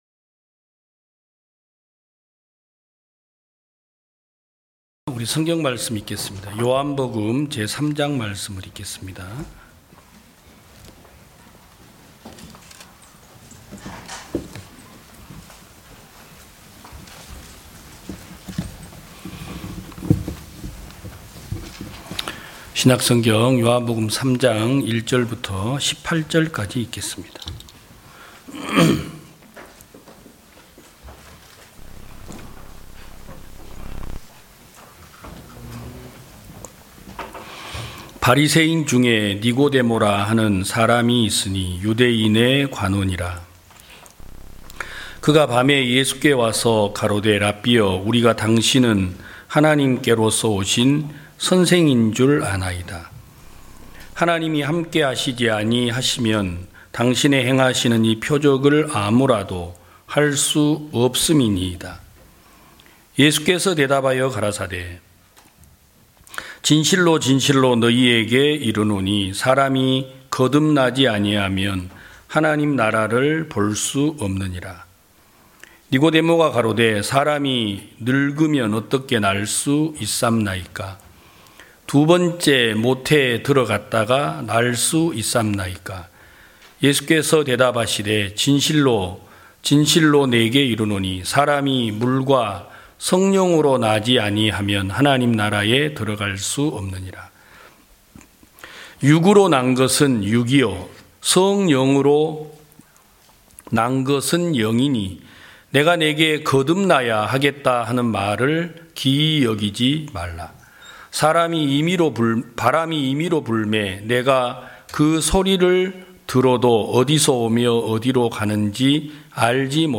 2022년 11월 06일 기쁜소식부산대연교회 주일오전예배
성도들이 모두 교회에 모여 말씀을 듣는 주일 예배의 설교는, 한 주간 우리 마음을 채웠던 생각을 내려두고 하나님의 말씀으로 가득 채우는 시간입니다.